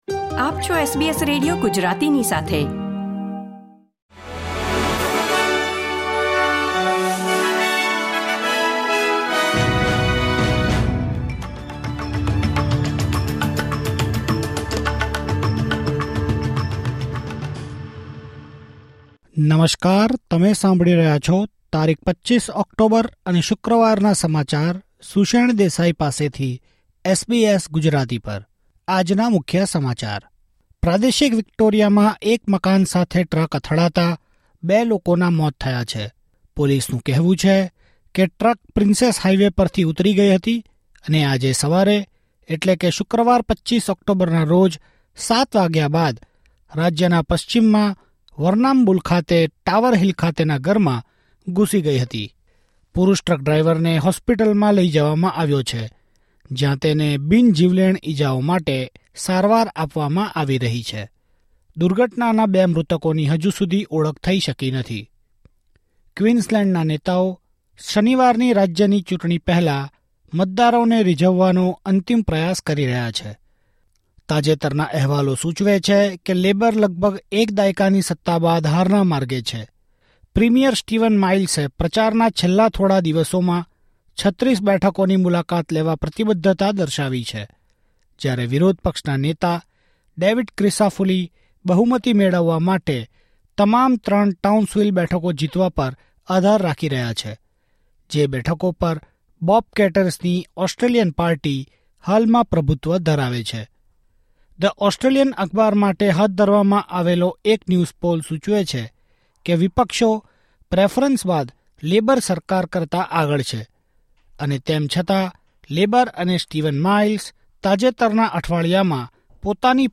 SBS Gujarati News Bulletin 25 October 2024